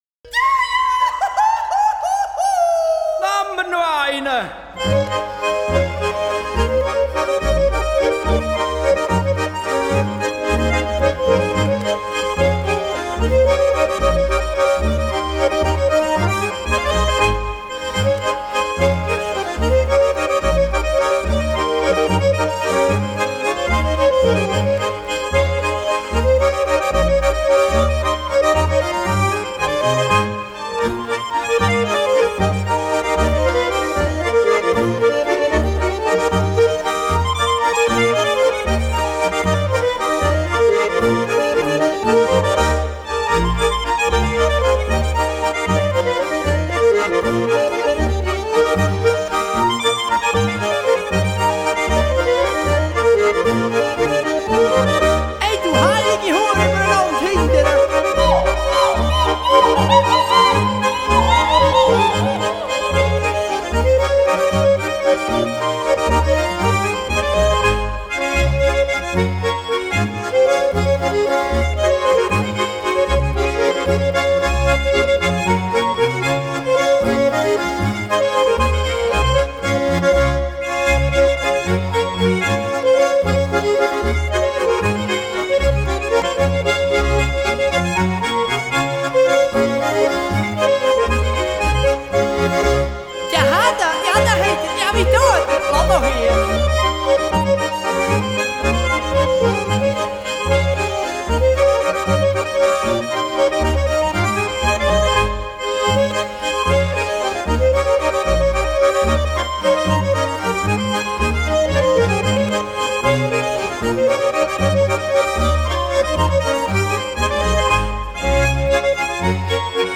Ländler